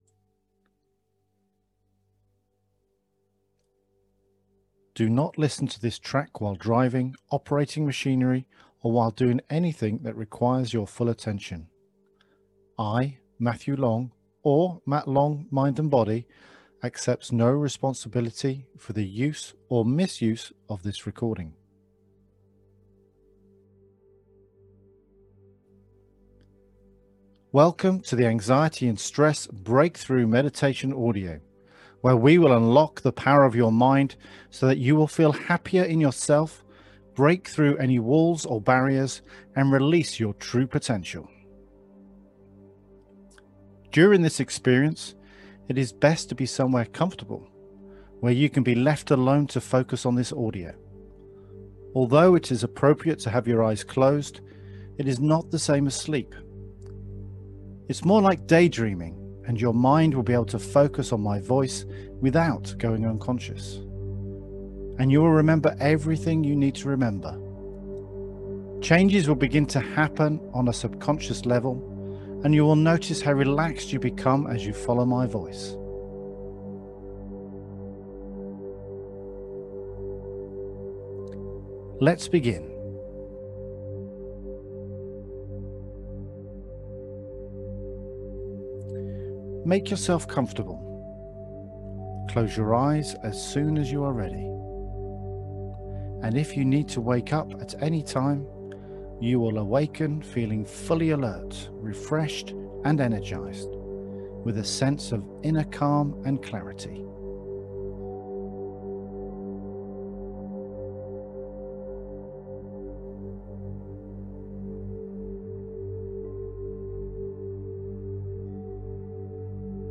The Anxiety and Stress Break-Through System Meditation Audio has been designed so to give you the maximum benefit in the relief from Anxiety and Stress.
The hypnotic language will caress your subconscious so that it helps all that Anxiety and Stress just melt away, leaving you feeling relaxed, calm, motivated and energised.